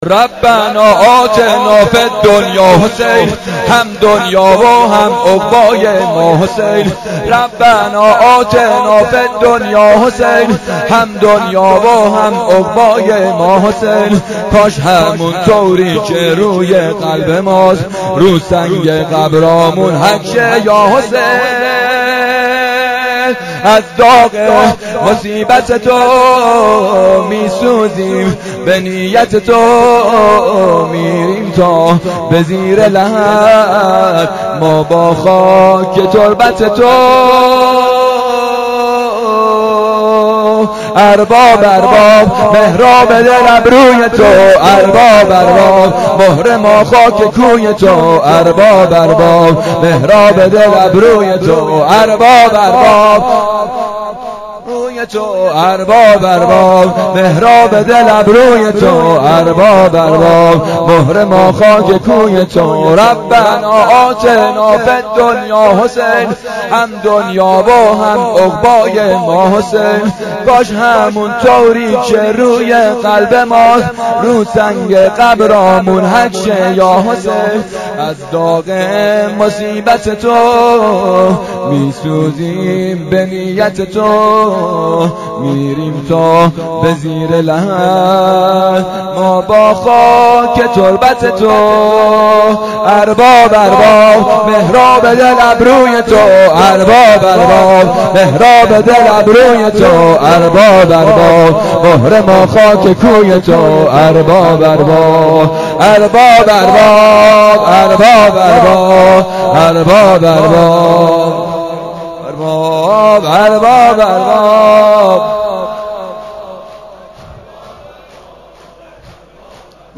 شب عاشورا محرم 93 حسینیه معراج الشهدا
شور 1: ربنا آتنا فی الدنیا حسین